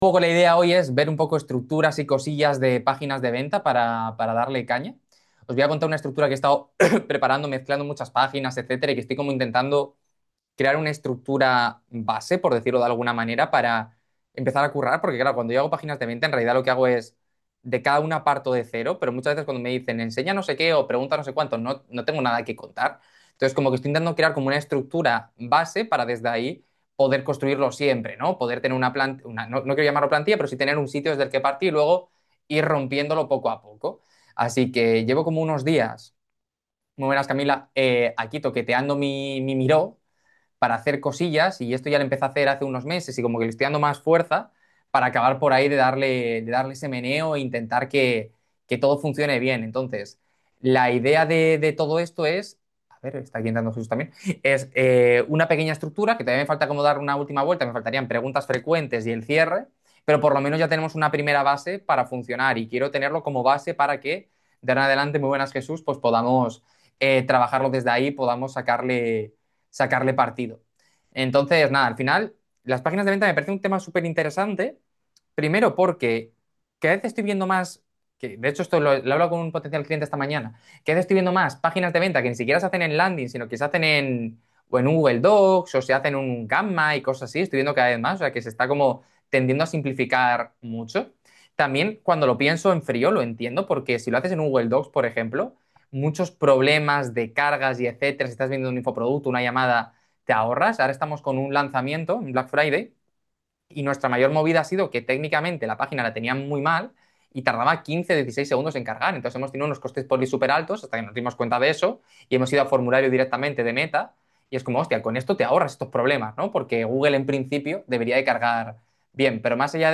MASTERCLASS | Páginas de venta (Copywriting para 2026)